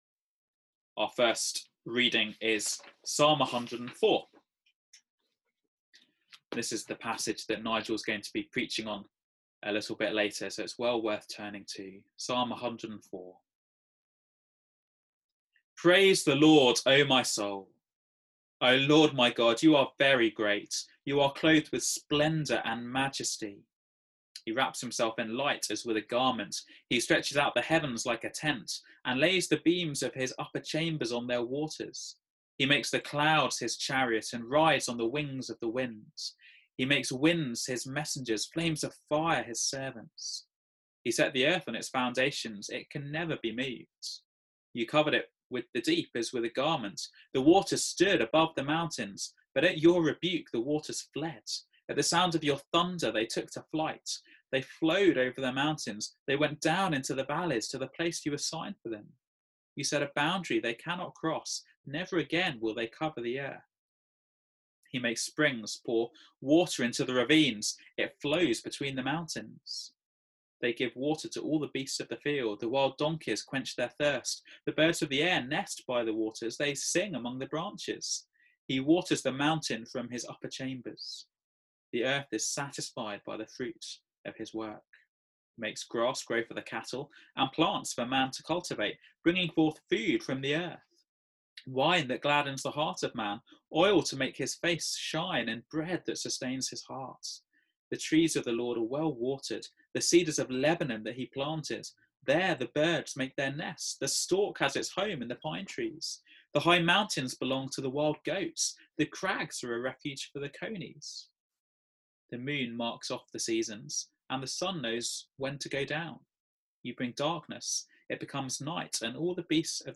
Does God Care for the Environment? Sermon Search: